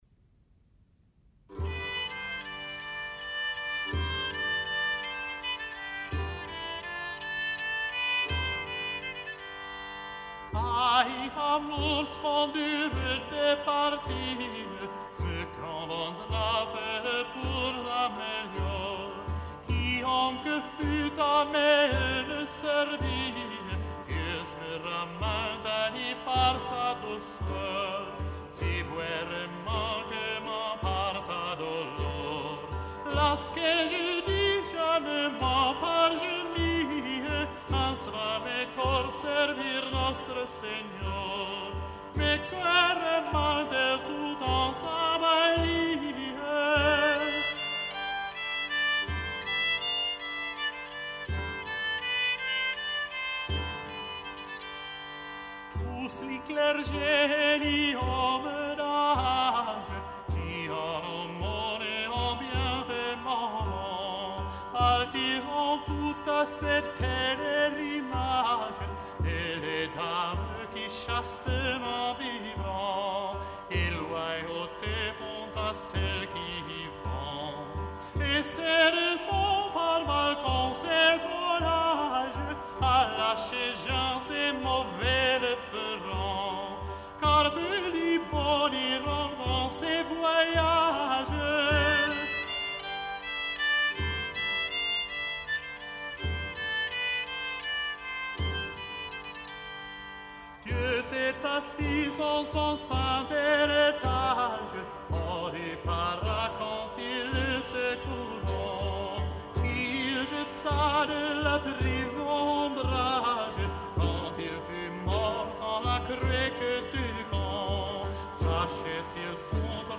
Una grande esortazione alla partenza